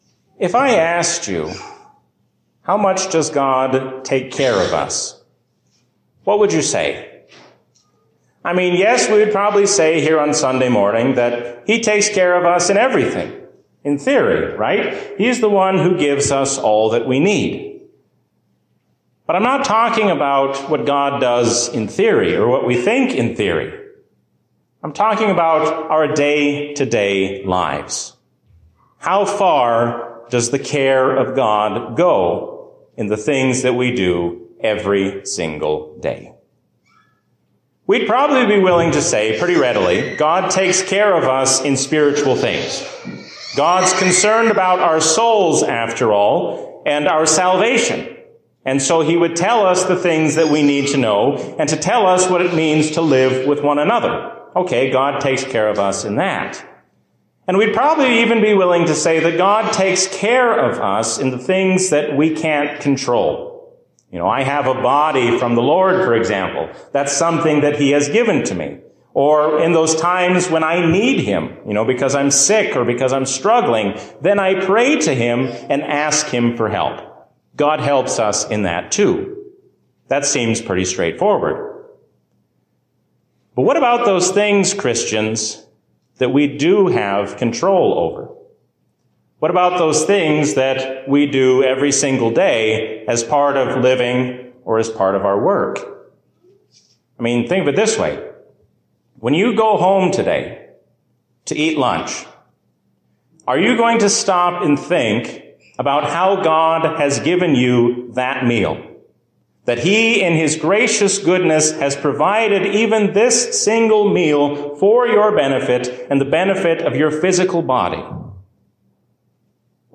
A sermon from the season "Trinity 2020." God cares for us in every need of body and soul.